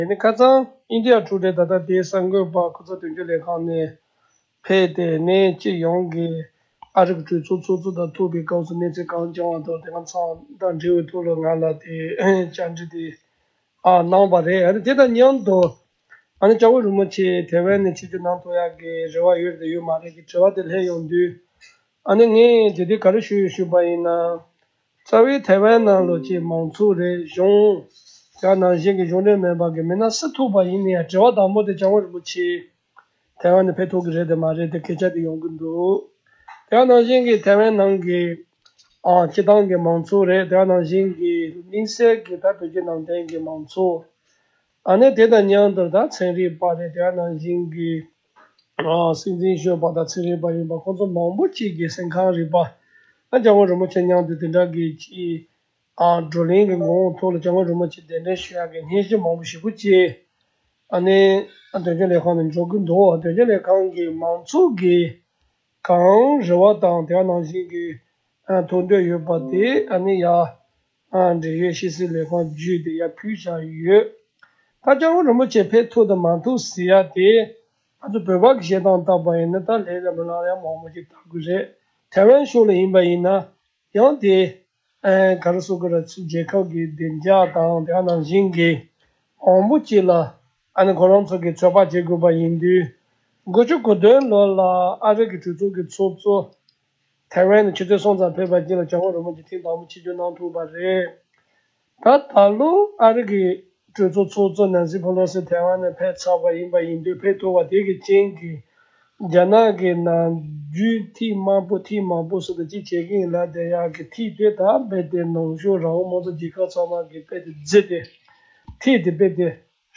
འབྲེལ་ཡོད་གནས་ཚུལ་ཐད་འདི་ག་རླུང་འཕྲིན་ཁང་གིས་ཐའེ་ཝན་སྐུ་ཚབ་དོན་གཅོད་སྐལ་བཟང་རྒྱལ་མཚན་ལགས་སུ་བཀའ་འདྲི་ཞུ་སྐབས་ཁོང་གིས་འདི་ལྟར་གསུངས་བྱུང་།
སྒྲ་ལྡན་གསར་འགྱུར། སྒྲ་ཕབ་ལེན།